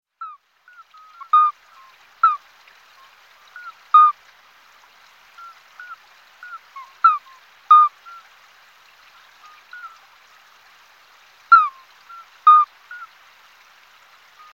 Alytes-obstetricansCall.mp3